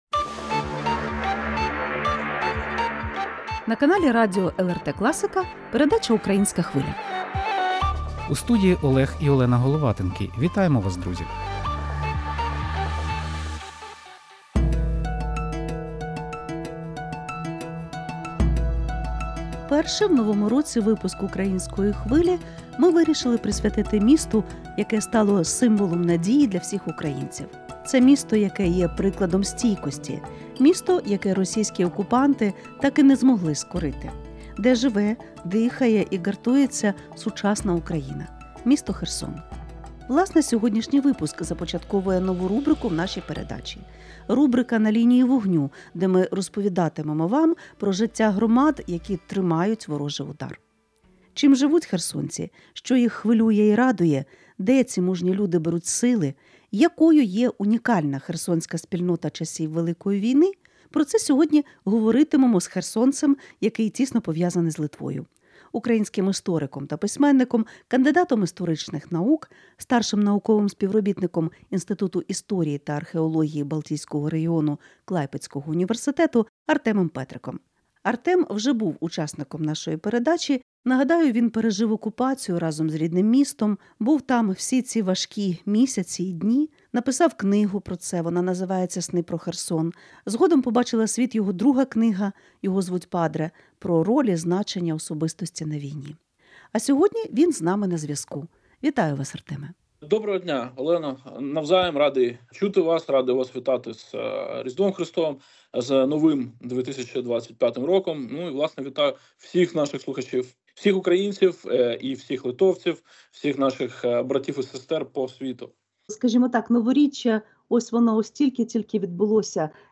Гість студії — український історик та письменник